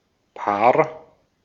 Ääntäminen
US : IPA : [pɛɹ]